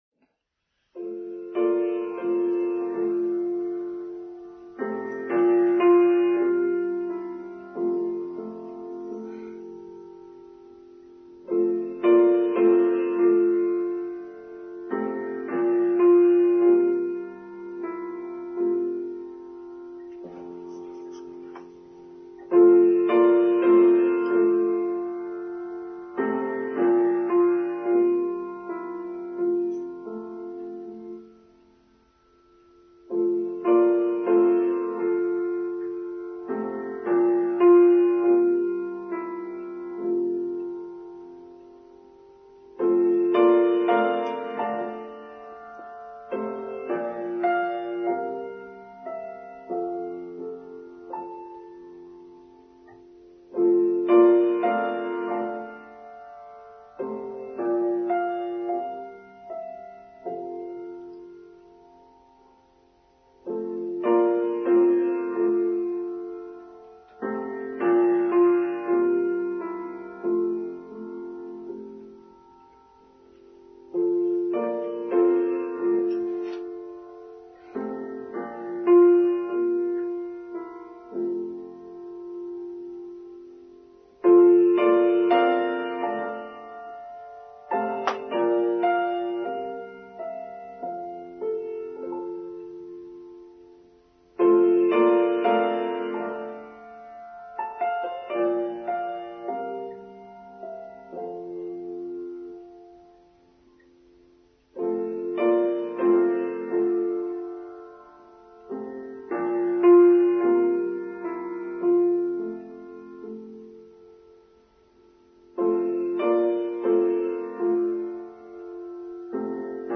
Rose Service: Online service for Sunday 3rd July 2022